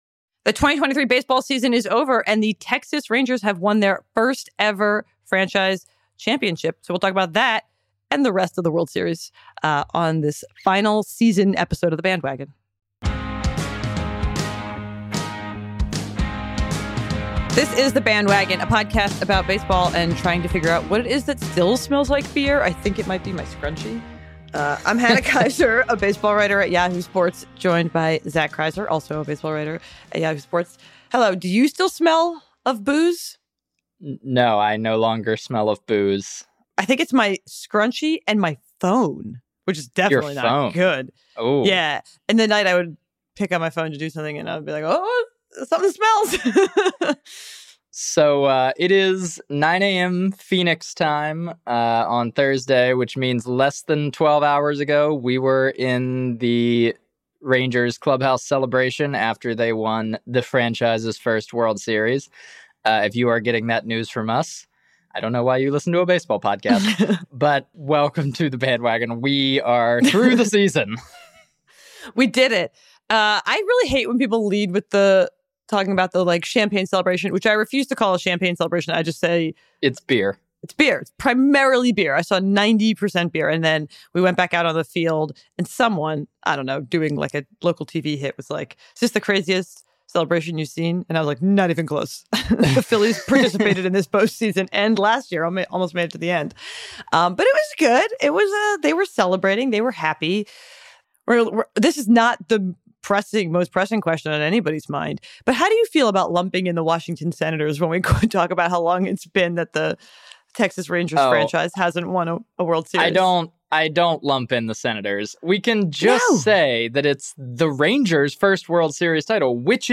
weekly conversation about the rapidly evolving world of baseball